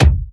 • Natural Steel Kick Drum C Key 15.wav
Royality free kickdrum sound tuned to the C note. Loudest frequency: 588Hz
natural-steel-kick-drum-c-key-15-5eD.wav